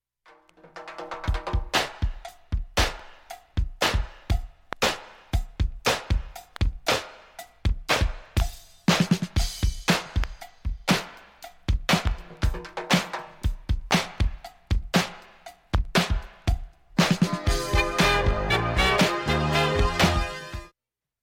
音質良好全曲試聴済み。
B-3始めかすかなプツが２回と７回出ます
重厚なシンセベースが印象的な